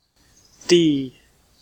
Ääntäminen
Ääntäminen letter name: IPA : /ˈdiː/ phoneme: IPA : /d/ Tuntematon aksentti: IPA : /ˈdi/ Haettu sana löytyi näillä lähdekielillä: englanti Määritelmät died , death .